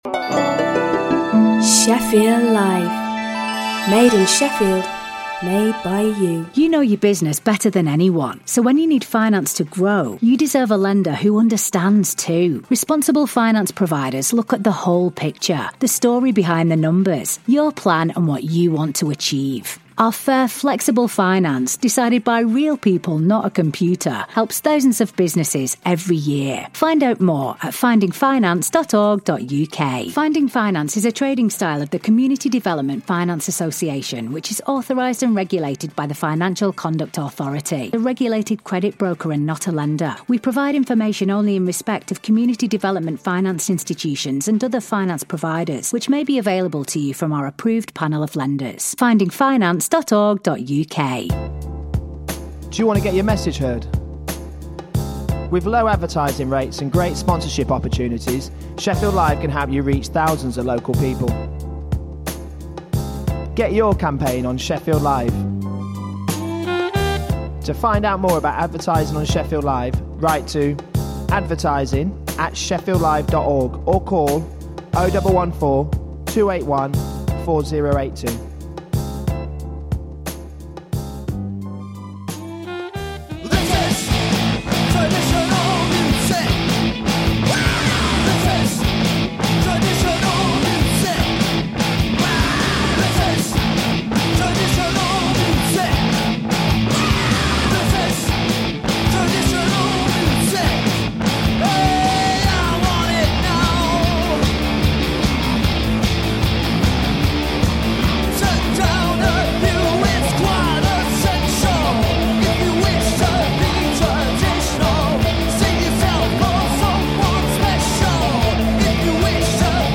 Playing the very best in urban music, new & old…